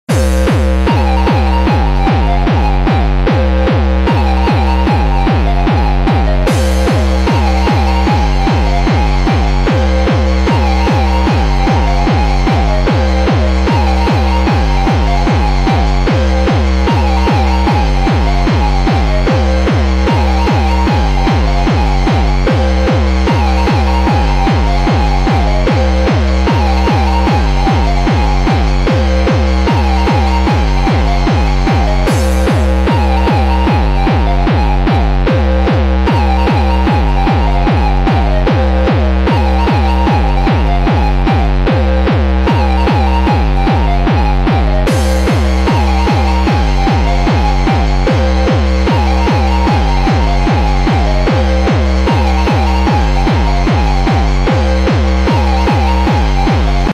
sorry about the quality lol..